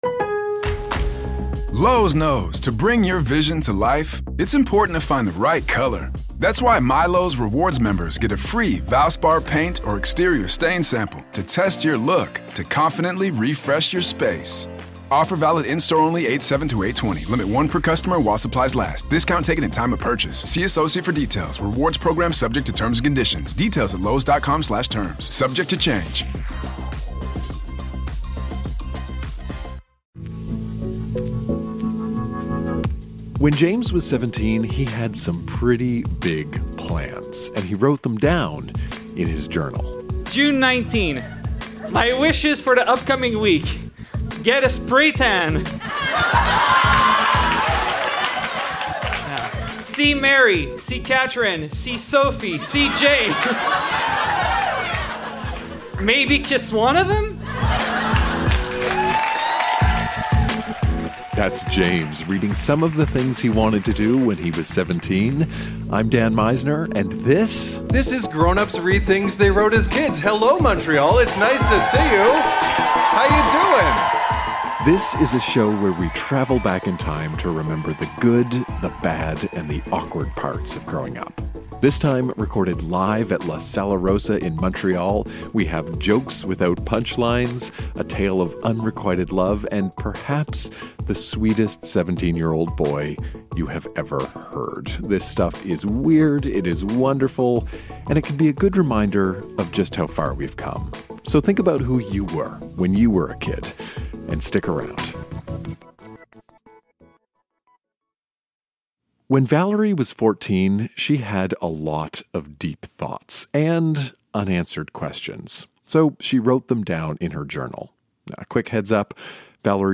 Recorded live at La Sala Rossa in Montréal.